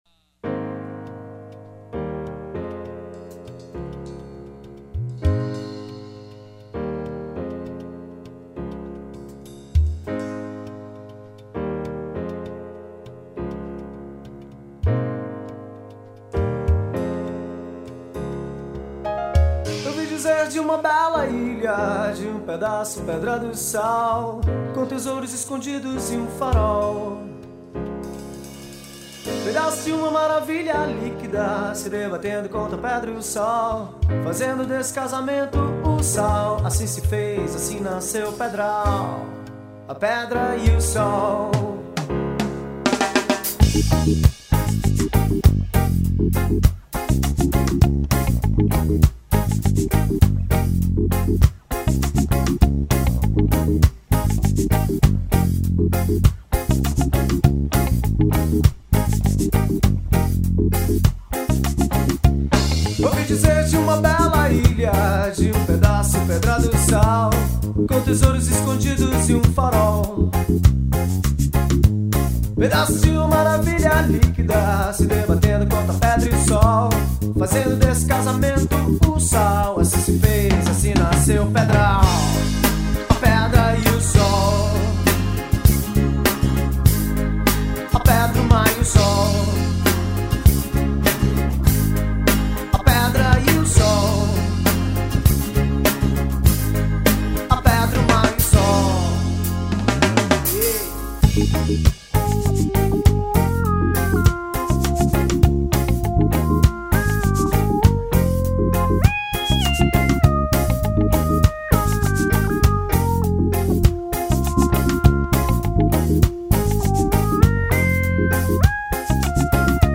1974   04:06:00   Faixa:     Reggae